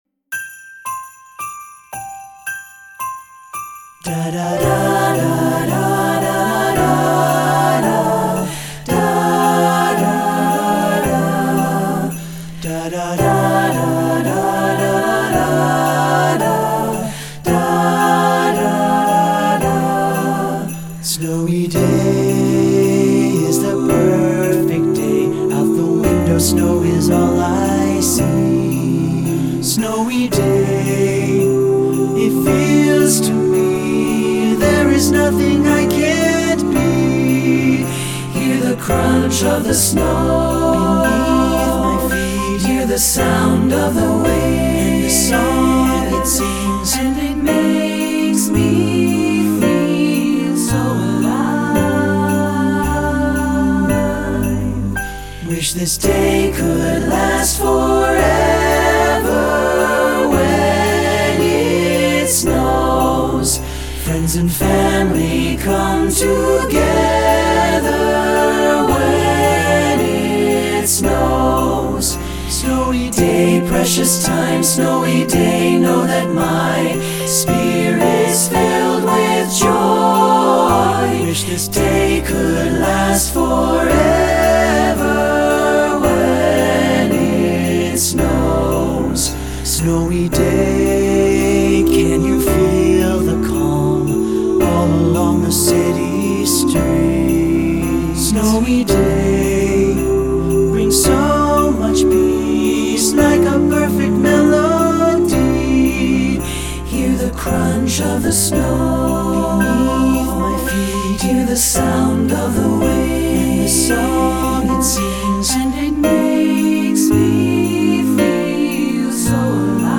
Voicing: TTB a cappella opt. Piano